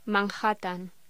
Locución: Manhattan
voz